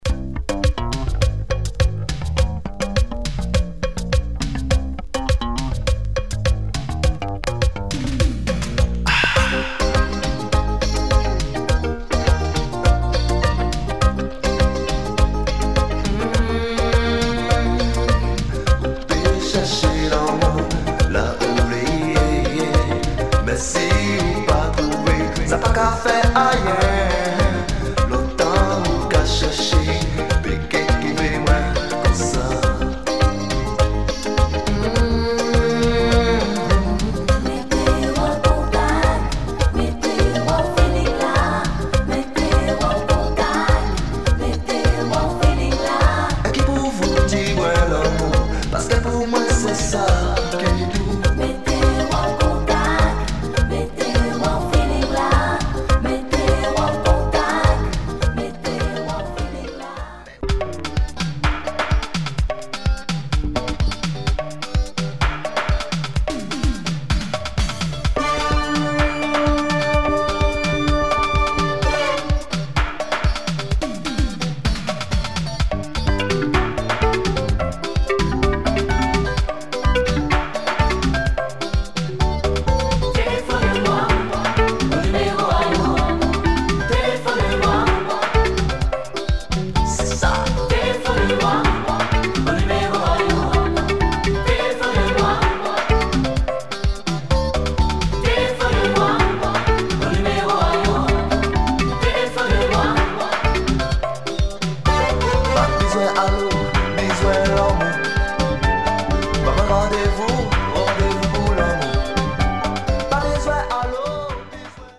Late 80's zouk
Caribbean